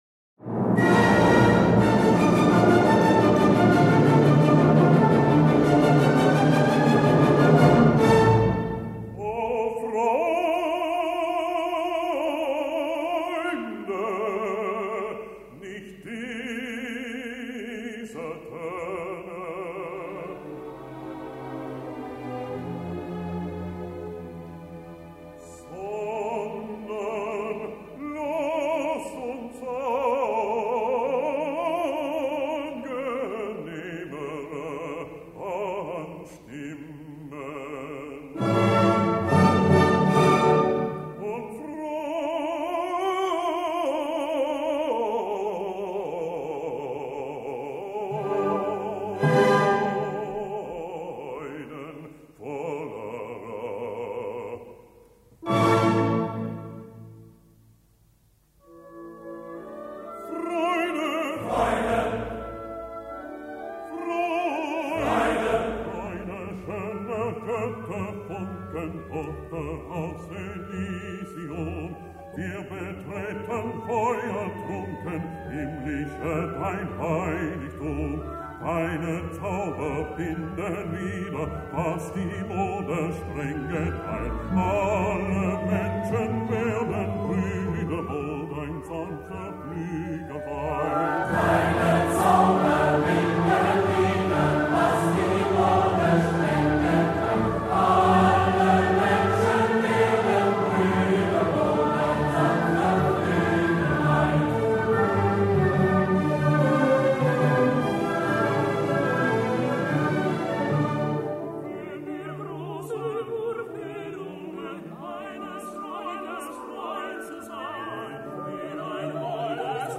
Berlin Philharmonic Orcheslra
cond. by André Cluytens